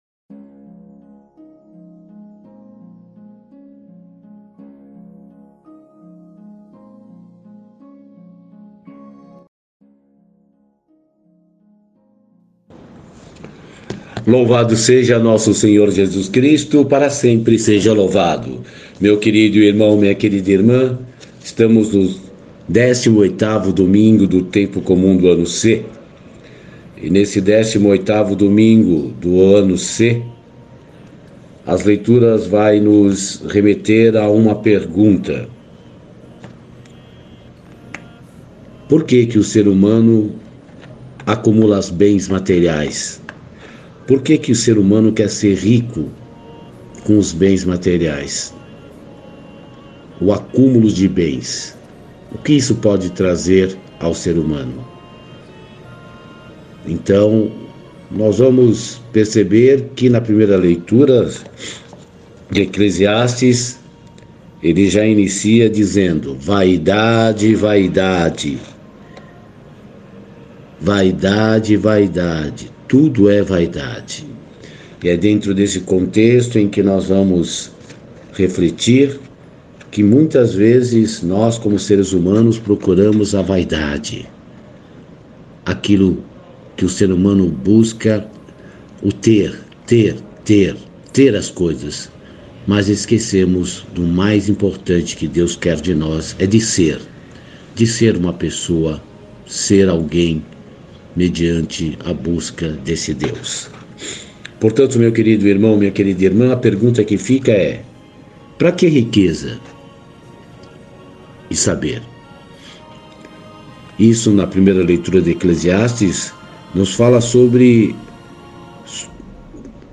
Reflexão e Meditação 18 Domingo Do Tempo Comum - Ano C